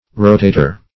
Rotator \Ro*ta"tor\, n. [L.]